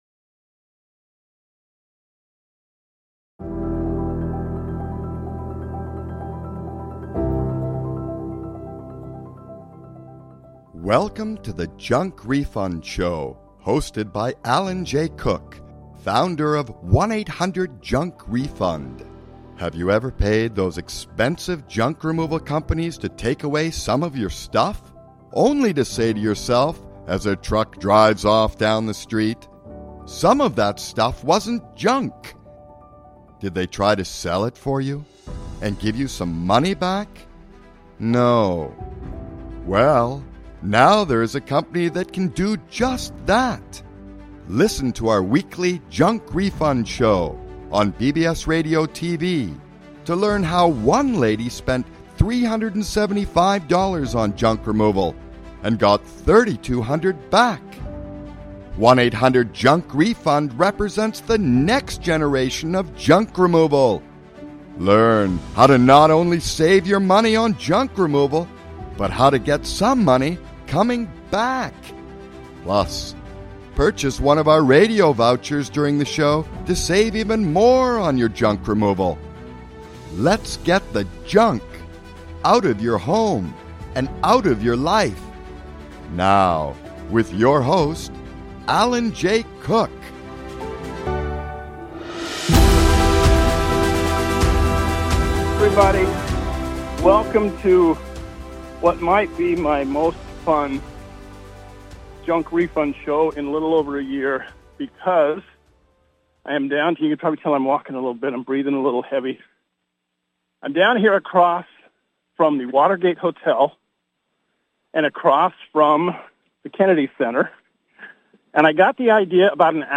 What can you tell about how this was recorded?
Taking Callers live on Set, across from Water Watergate hotel and Kennedy Center at the National Mall.